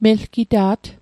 Pronunciation Guide: mel·ki·daad Translation: He/she is courageous